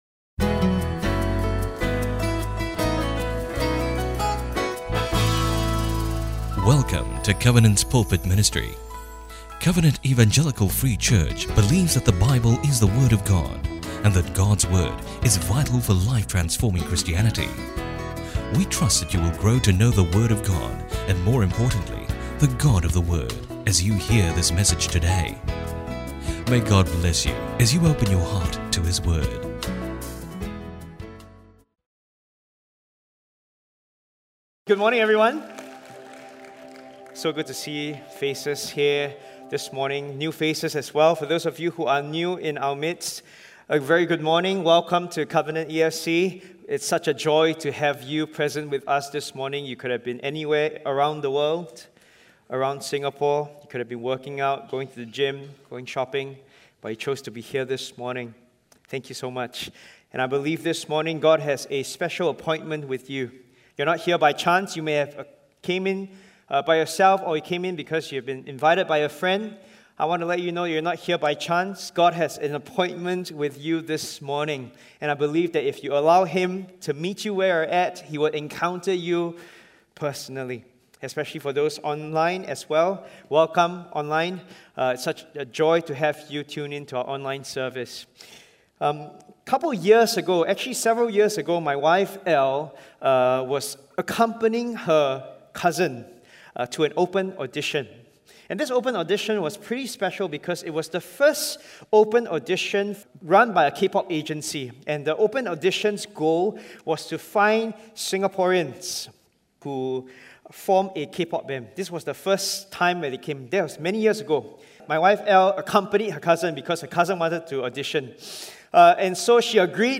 We would like to continue extending our audio sermons to as many as possible.